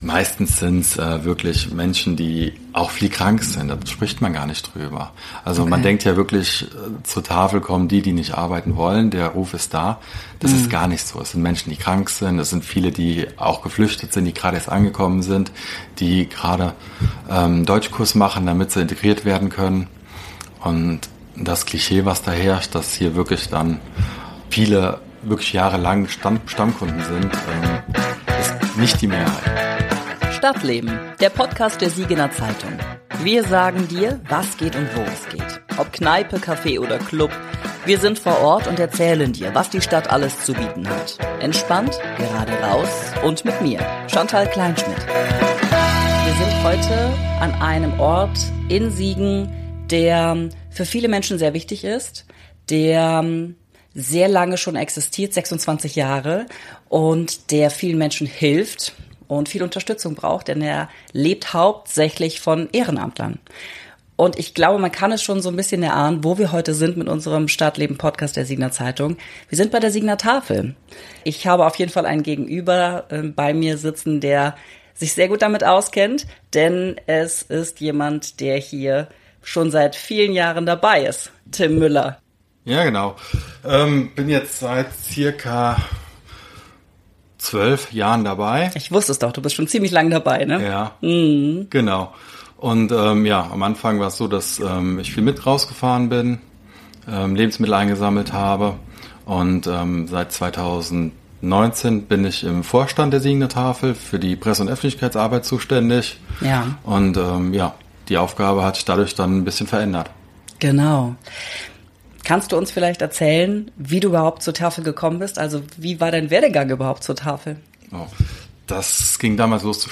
Das stellt die Tafel – vor allem in Zeiten von Krieg, einem hohen Altersdurchschnitt bei den Ehrenamtlern und raueren Ton seitens der Gäste – vor Herausforderungen. Mit unserem „Stadtleben“- Podcast sind wir diesmal an der Bismarckstraße unterwegs und stellen euch eine der größten sozialen Anlaufstellen in der Krönchenstadt vor.